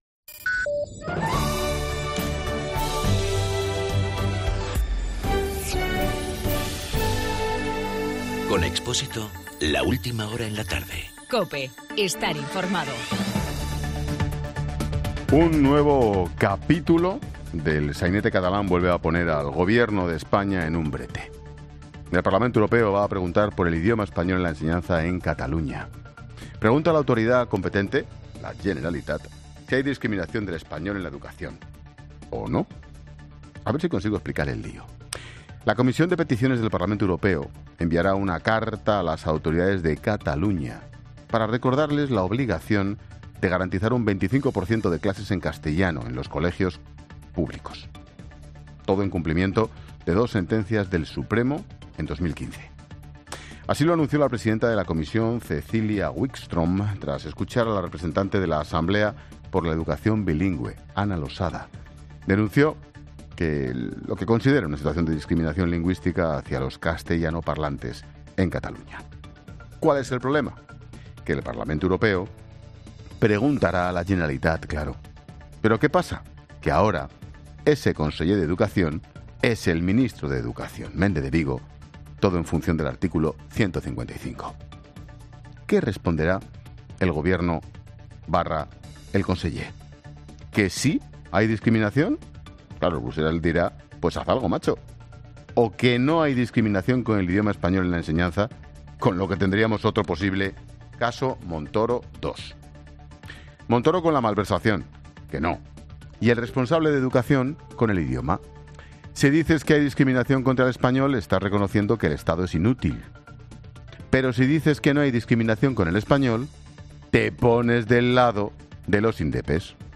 Monólogo de Expósito
Ángel Expósito comenta a las 17 horas el último capítulo del sainete catalán.